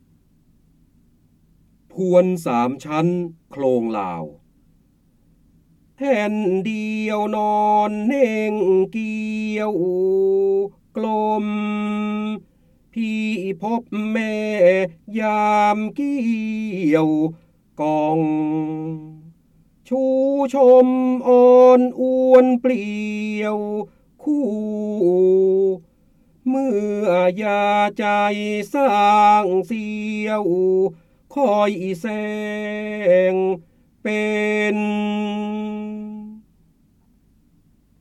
เสียงบรรยายจากหนังสือ จินดามณี (พระโหราธิบดี) พวนสามชั้นโคลงลาว
คำสำคัญ : พระเจ้าบรมโกศ, พระโหราธิบดี, การอ่านออกเสียง, จินดามณี, ร้อยแก้ว, ร้อยกรอง